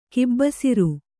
♪ kibbasiru